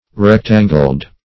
Rectangled \Rec"tan`gled\ (-g'ld), a.